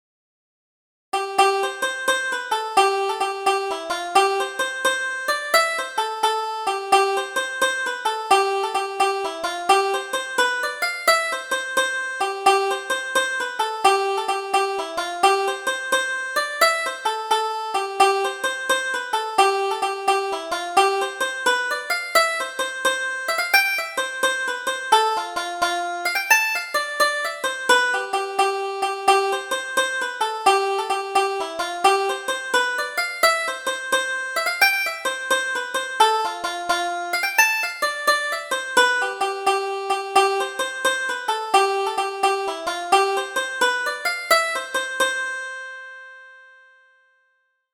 Double Jig: The Penniless Traveller